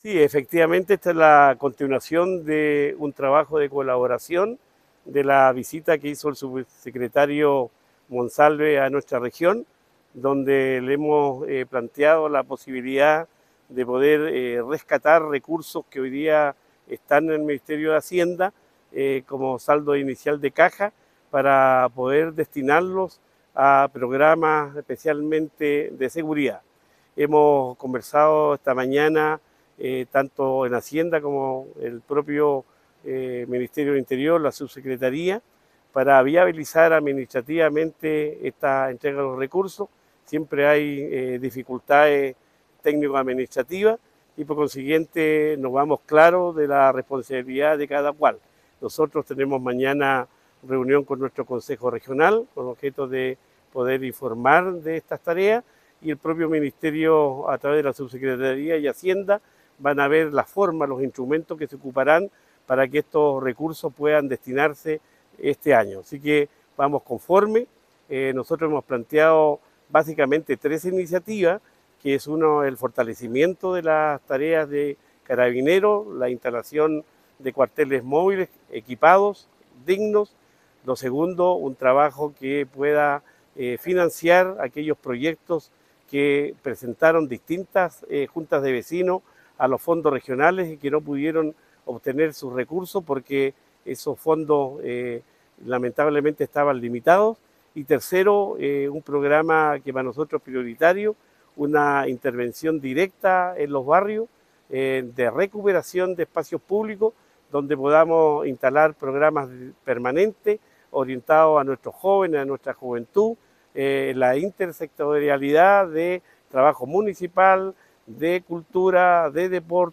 Cuña_Gobernador-Luis-Cuvertino_gestiones-Stgo_22-agosto.m4a